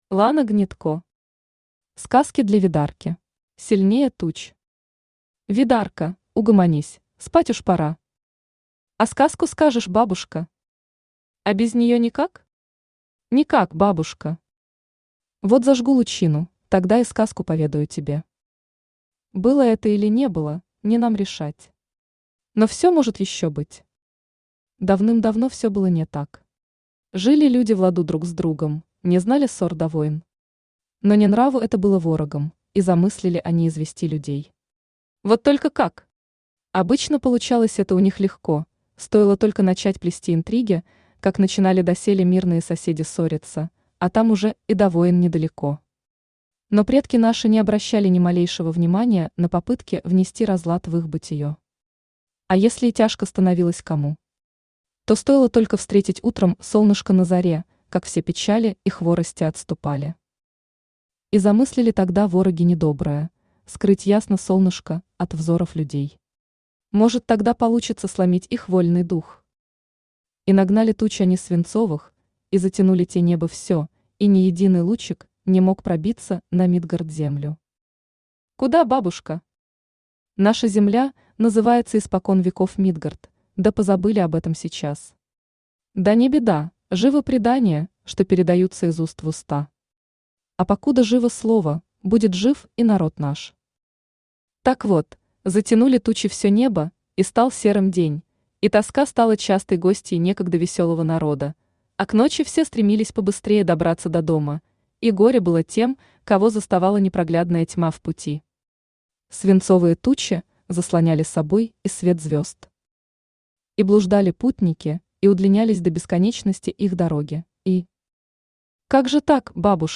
Аудиокнига Сказки для Ведарки | Библиотека аудиокниг
Aудиокнига Сказки для Ведарки Автор Лана Гнедко Читает аудиокнигу Авточтец ЛитРес.